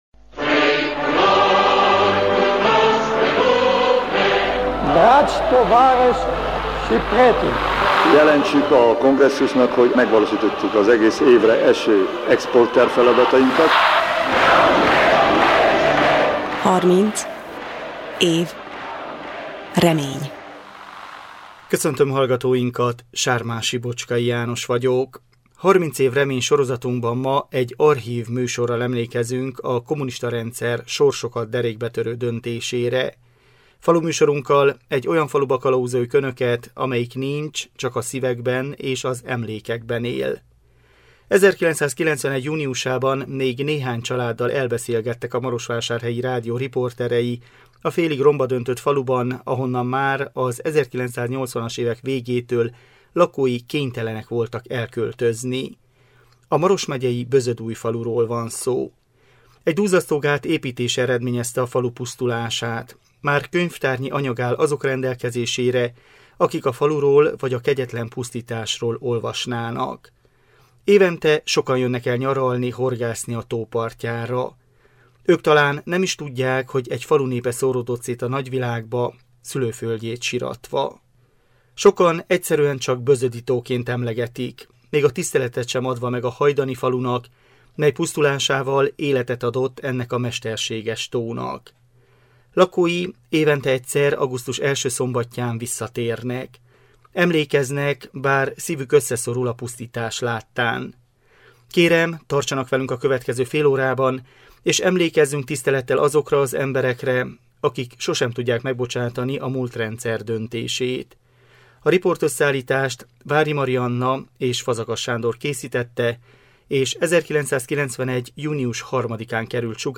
Faluműsorunkkal egy olyan faluba kalauzoljuk önöket, amelyik nincs, csak a szívekben és az emlékekben él. 1991 júniusában még néhány családdal elbeszélgettek a Marosvásárhelyi Rádió riporterei a félig romba döntött faluban, ahonnan már az 1980-as évek végétől lakói kénytelenek voltak elköltözni.
Műsorunk az 1991 június 3-i műsor ismétlése!